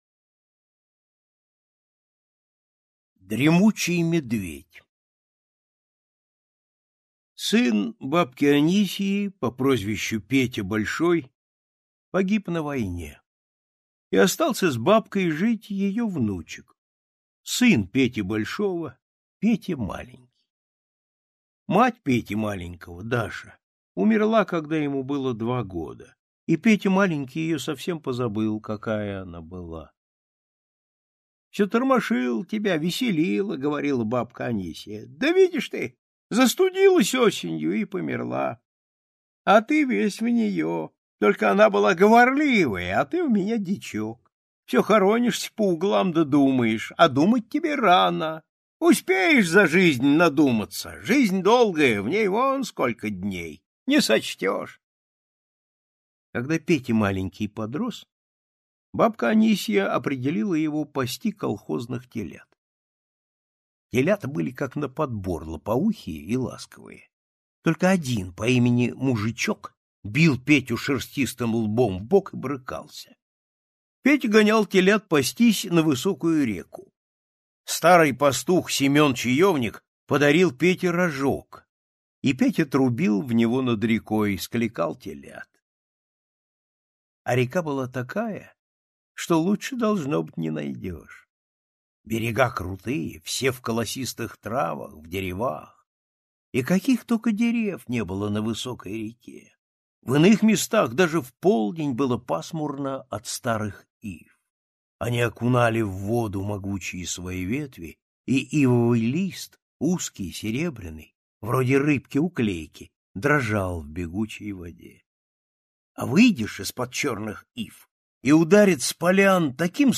Слушайте Дремучий медведь - аудио рассказ Паустовского К. Рассказ про мальчика Петю, который очень любит животных и много мечтает.